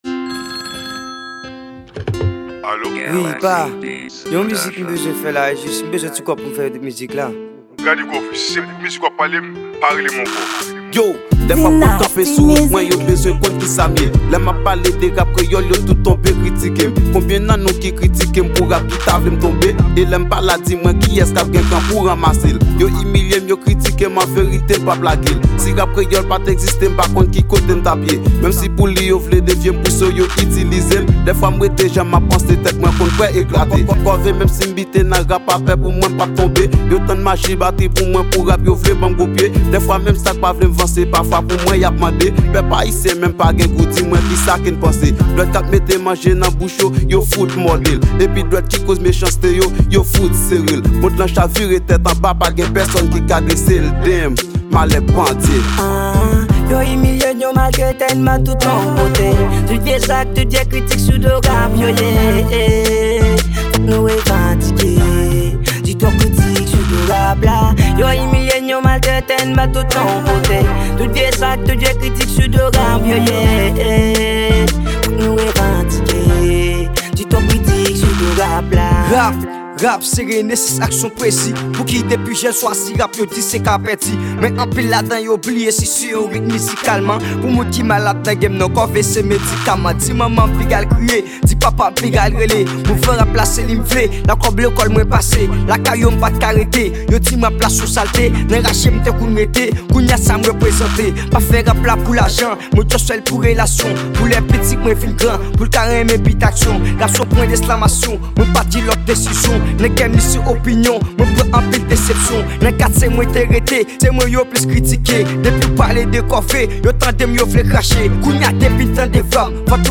Genre: Rap-Rnb.